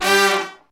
G3 POP FALL.wav